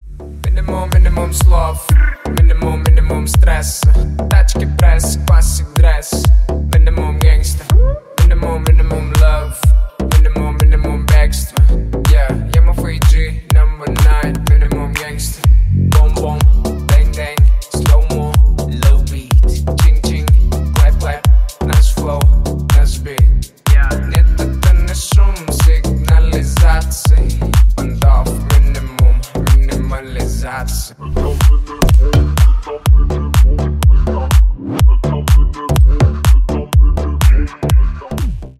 Ремикс
Танцевальные